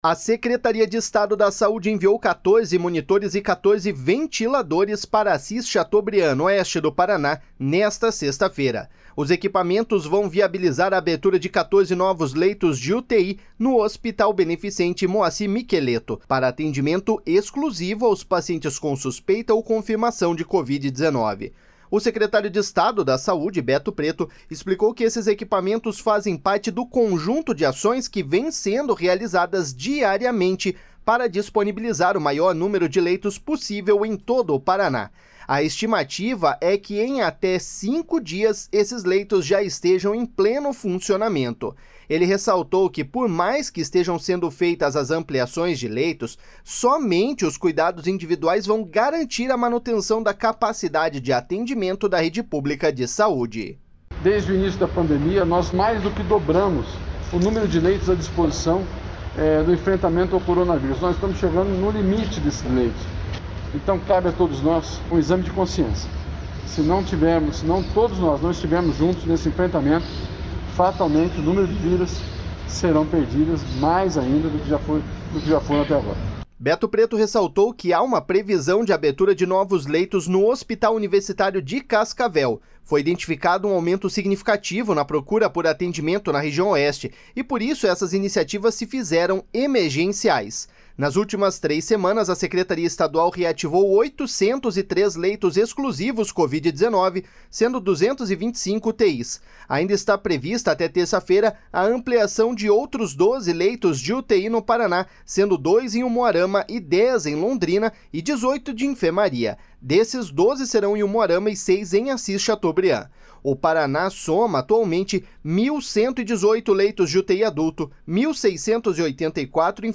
Ele ressaltou que, por mais que estejam sendo feitas as ampliações de leitos, somente os cuidados individuais vão garantir a manutenção da capacidade de atendimento da rede pública de saúde.//SONORA BETO PRETO.//